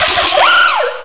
pokeemerald / sound / direct_sound_samples / cries / drilbur.aif